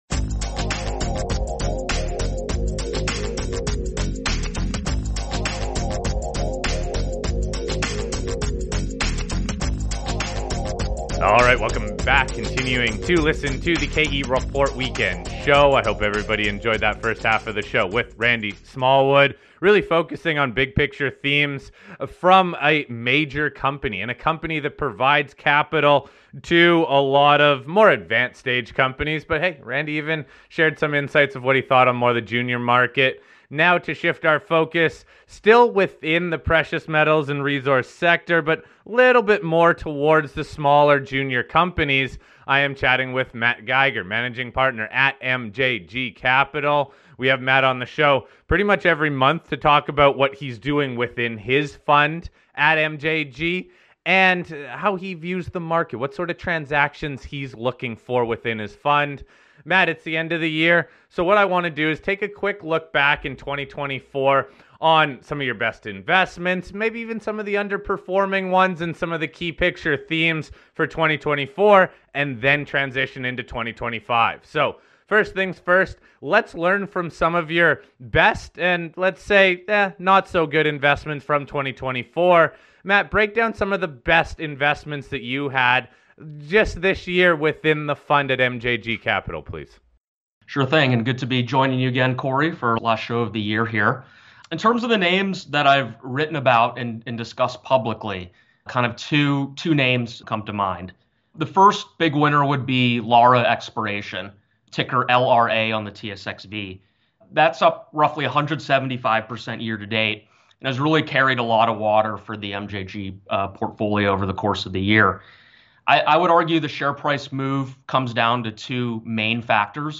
The show also explores current topics at the intersection of economics and politics, and features a fascinating array of economists, writers, and industry experts.